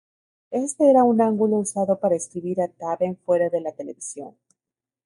Read more angle Frequency C1 Hyphenated as án‧gu‧lo Pronounced as (IPA) /ˈanɡulo/ Etymology Borrowed from Latin angulus In summary Borrowed from Latin angulus.